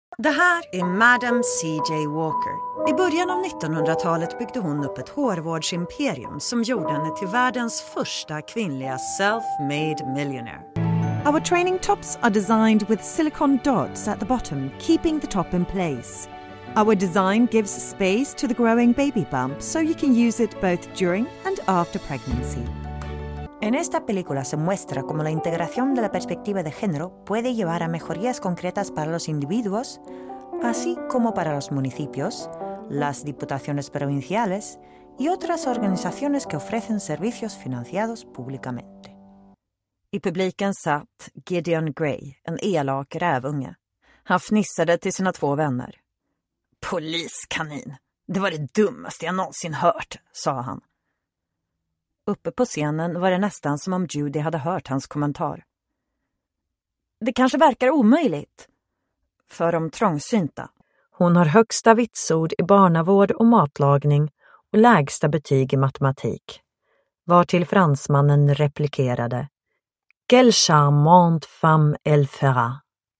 Male 30s , 40s , 50s American English (Native) , Flemish (Native) Approachable , Assured , Authoritative , Bright , Character , Confident , Conversational , Cool , Corporate , Deep , Energetic , Engaging , Friendly , Funny , Gravitas , Natural , Posh , Reassuring , Sarcastic , Smooth , Soft , Upbeat , Versatile , Wacky , Warm , Witty Animation , Character , Commercial , Corporate , Documentary , Educational , E-Learning , Explainer , IVR or Phone Messaging , Narration , Training , Video Game